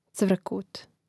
cvȑkūt cvrkut